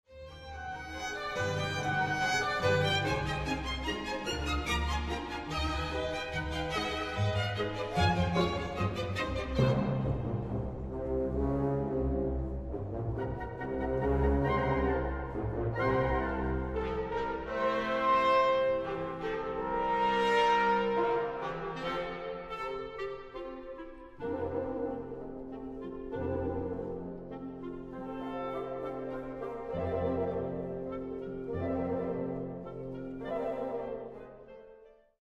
Scherzo rozpoczyna się obiecująco.